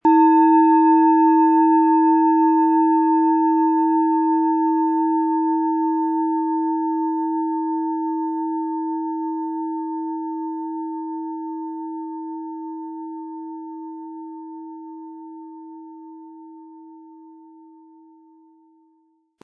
Planetenschale® Innere Tiefe fühlen & In den Bauch spüren mit Alphawellen & Mond, Ø 15,4 cm, 600-700 Gramm inkl. Klöppel
Diese tibetische Planetenschale Alphawelle ist von Hand gearbeitet.
• Tiefster Ton: Mond
PlanetentöneAlphawelle & Mond
MaterialBronze